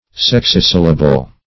\Sex"i*syl`la*ble\
sexisyllable.mp3